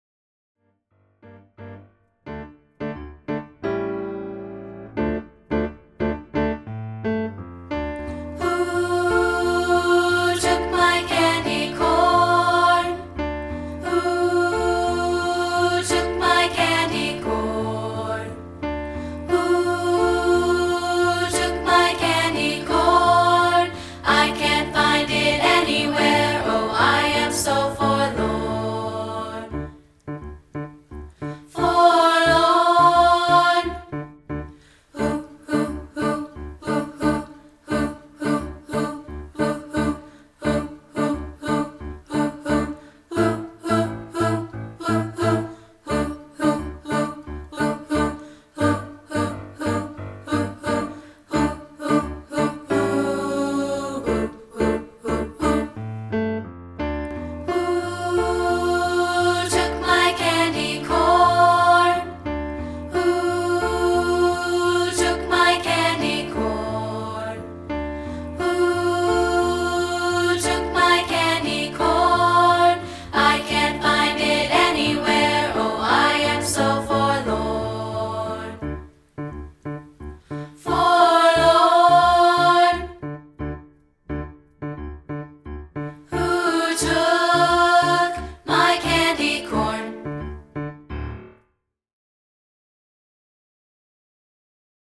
including a rehearsal track of part 2, isolated